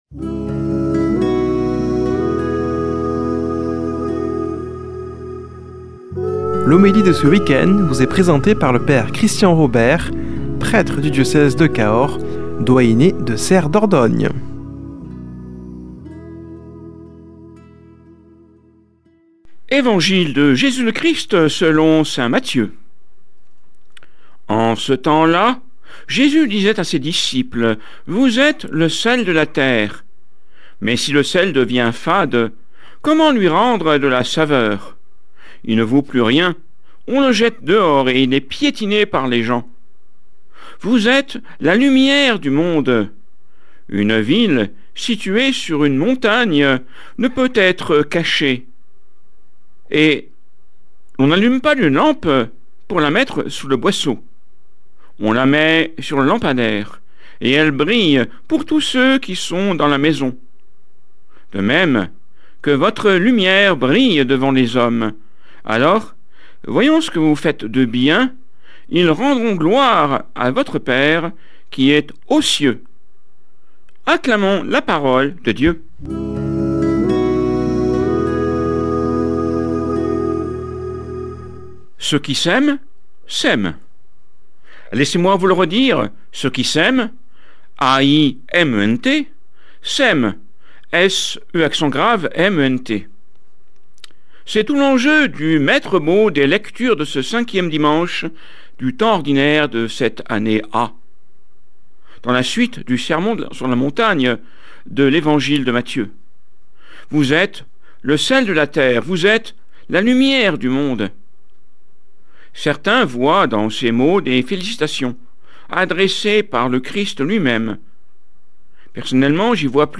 Homélie du 07 févr.
Présentateur